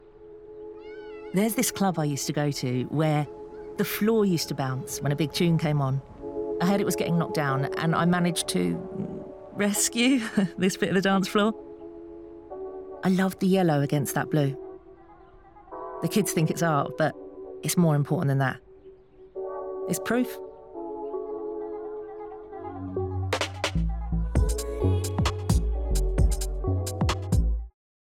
Natural, Llamativo, Accesible, Versátil, Cálida
Comercial